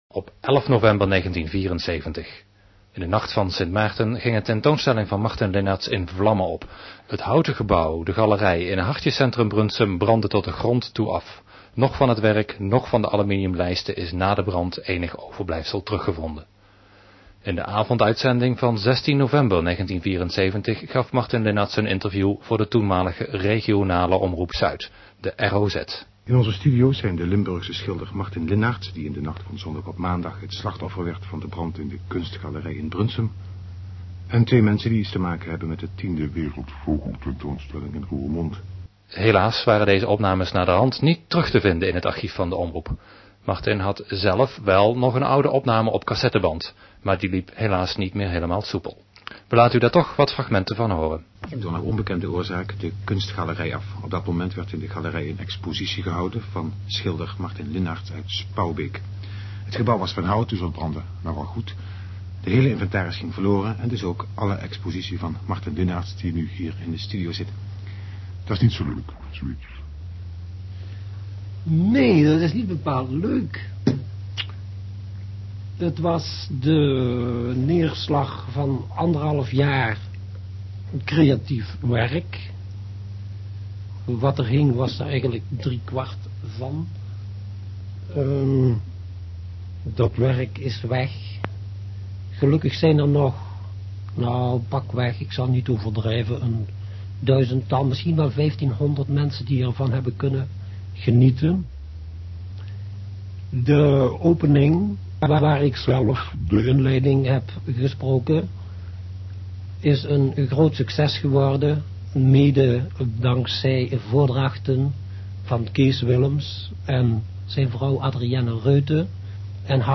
11 November 1974: De Brand Meer over de brand Interview 16 nov 1974: mp3 1MB Interview 11 mei 2002: mp3 0.6MB HOME | Oeuvre | Geluidsfragmenten | Levensloop